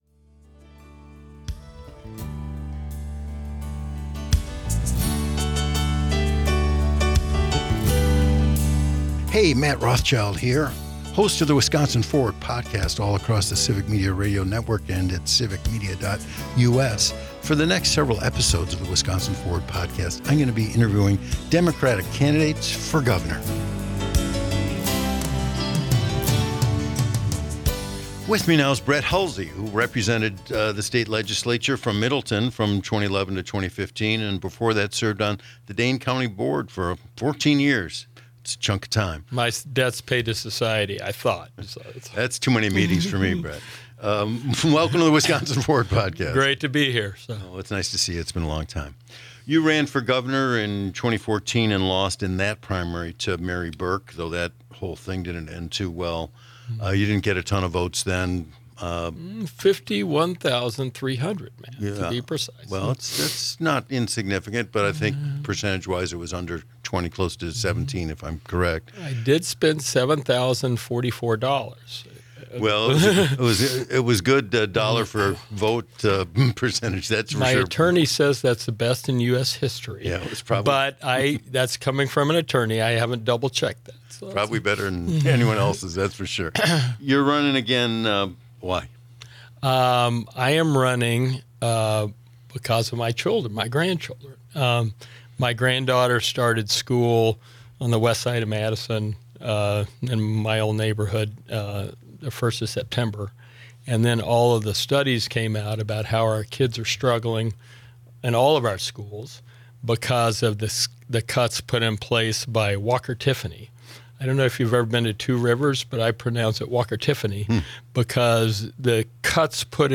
An Interview with Brett Hulsey - Civic Media